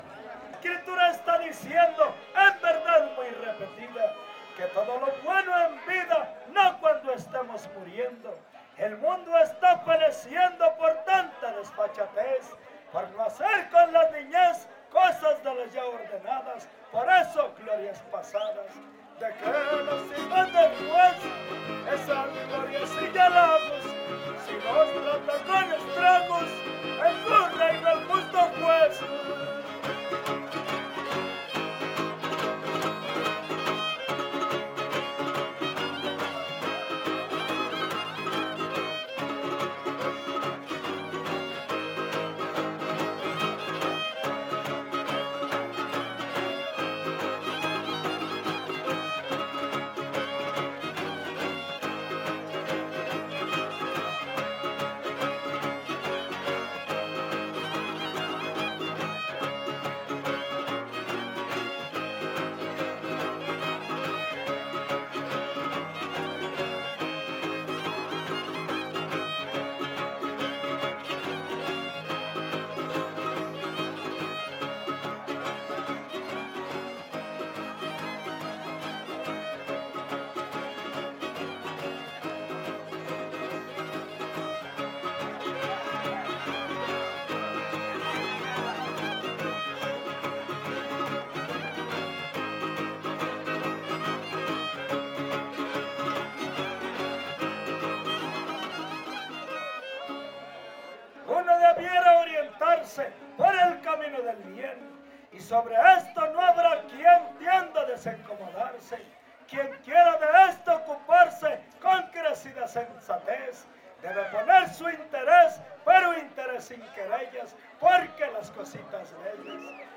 Huapango arribeño Décima
Vihuela Violín Guitarra
Topada ejidal: Cárdenas, San Luis Potosí